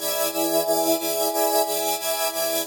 SaS_MovingPad02_90-E.wav